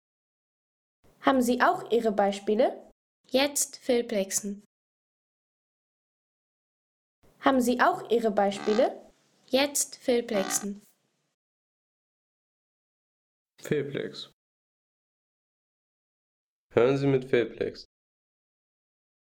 Die Hühnergans in ihrem Revier